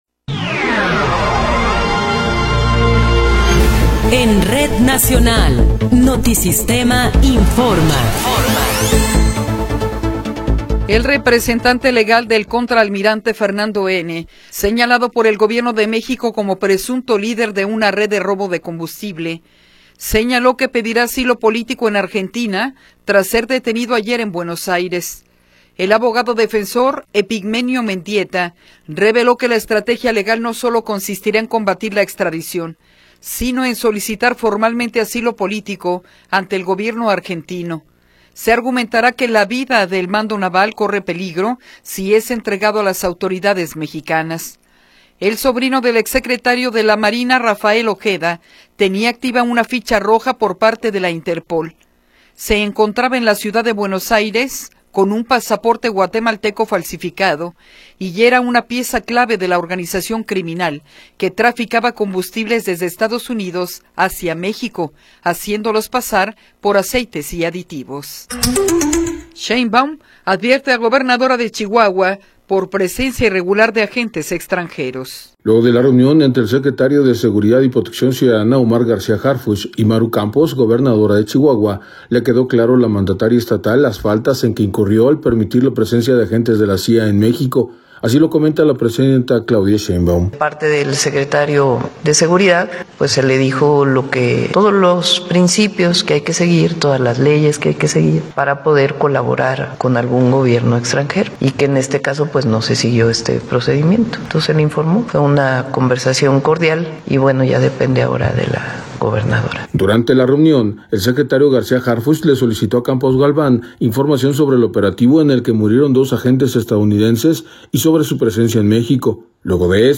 Noticiero 10 hrs. – 24 de Abril de 2026